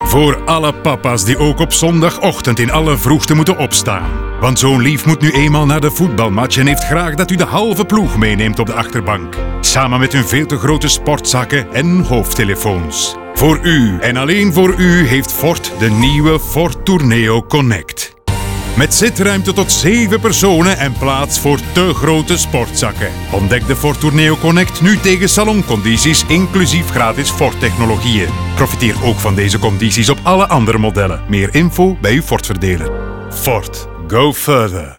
Ogilvy célèbre les héros du quotidien pour le lancement du nouveau Ford Tourneo Connect, une voiture familiale spacieuse qui peut embarquer jusqu’à 7 passagers. L’agence a ainsi créé deux spots radio épiques qui rendent hommage à toutes les mamans et tous les papas qui, chaque week-end et mercredi après-midi, passent leur temps à rouler d’un point à un autre.